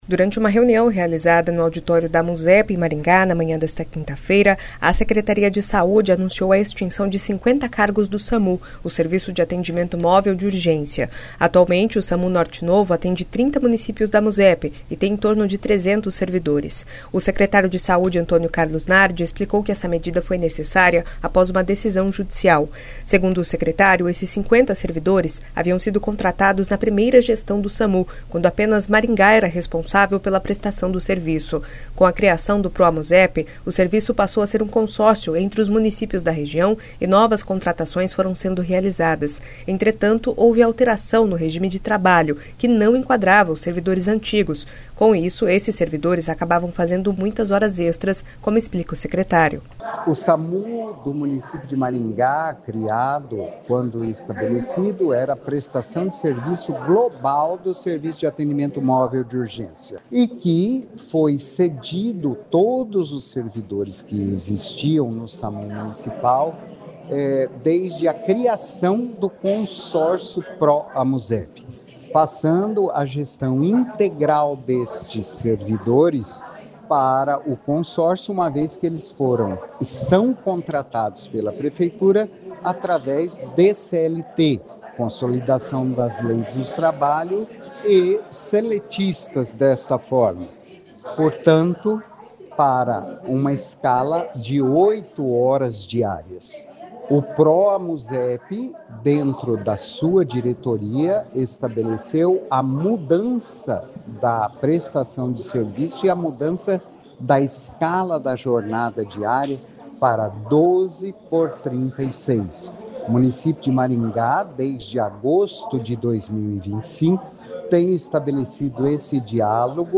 Entretanto, houve a alteração no regime de trabalho que não enquadrava os servidores antigos, com isso os servidores acabavam fazendo muitas horas extras, como explica o secretário.